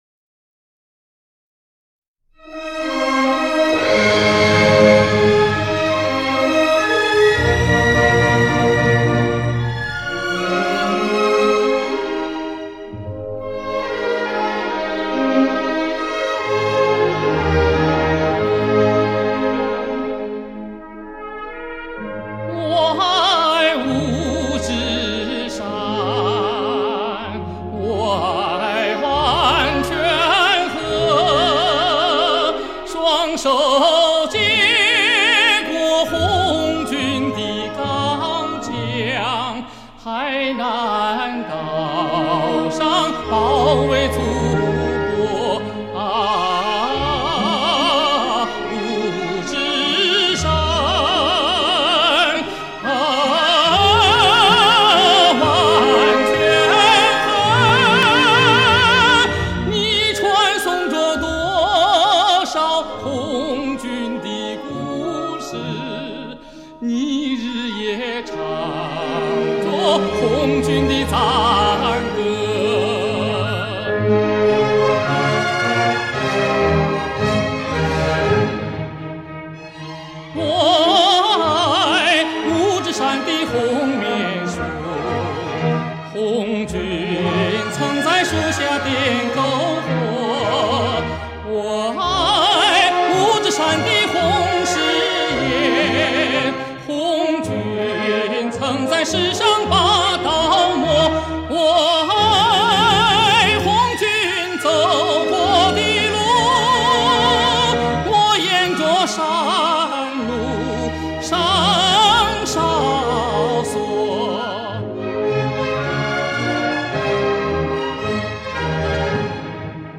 这次使用了20Bit Digital K2 Rubidium Clock Master技术以数位化K2处理，
音质达到了接近原始音乐母带的完美的再现